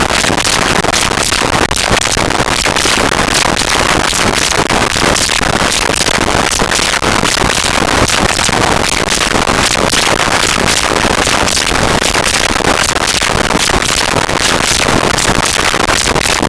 Heartbeat Slow
Heartbeat Slow.wav